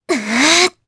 Epis-Vox_Casting2_jp.wav